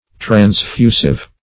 Transfusive \Trans*fu"sive\, a. Tending to transfuse; having power to transfuse.